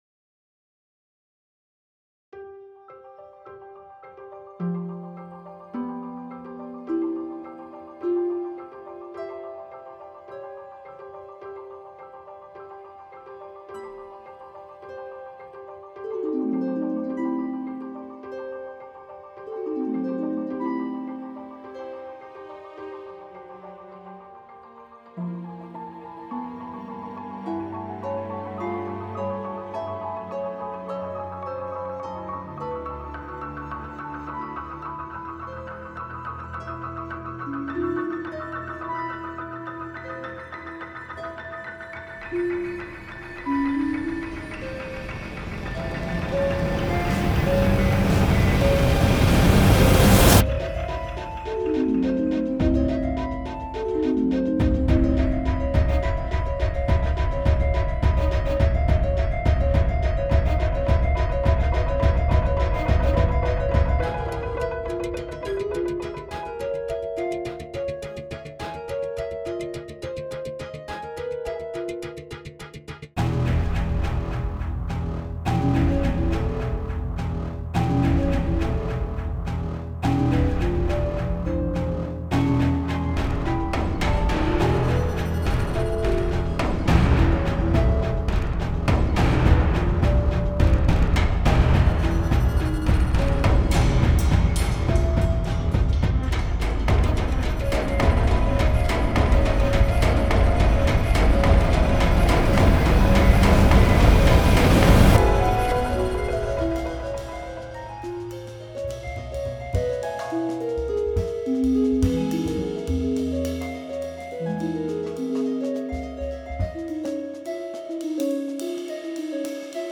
Composition for 2 Harps
preliminary MIDI version